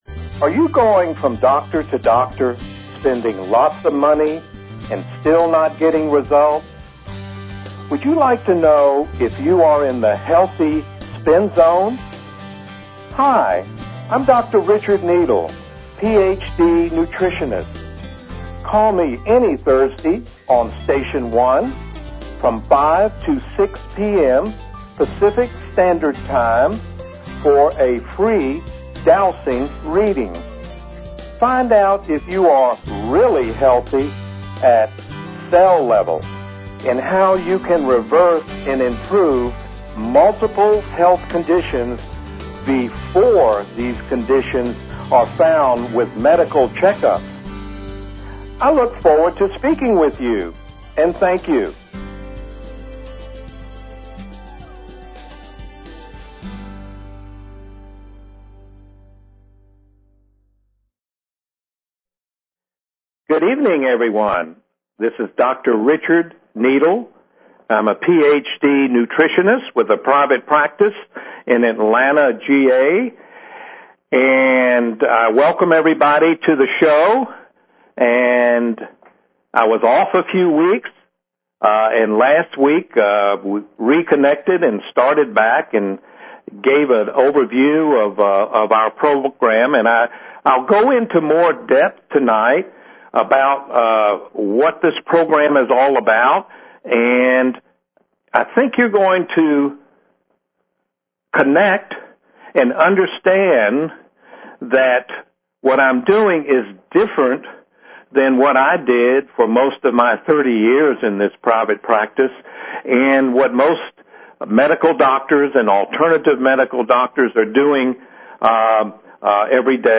Talk Show Episode, Audio Podcast, Stop_Guessing_About_Your_Health and Courtesy of BBS Radio on , show guests , about , categorized as